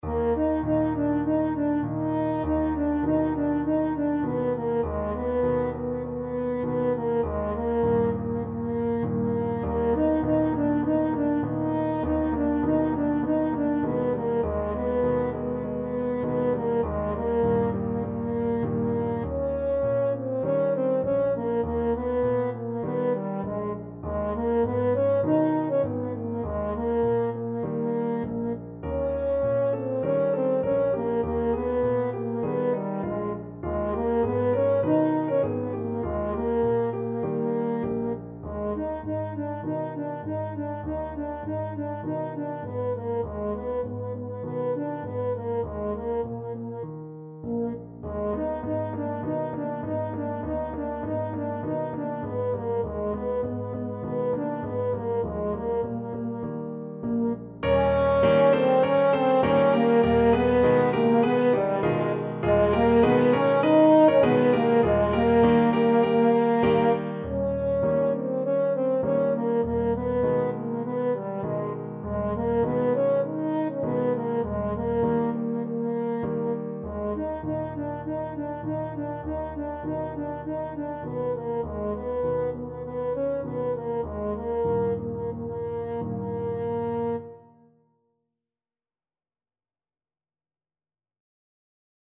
French Horn
Traditional Music of unknown author.
Eb major (Sounding Pitch) Bb major (French Horn in F) (View more Eb major Music for French Horn )
4/4 (View more 4/4 Music)
G4-Eb5
Moderato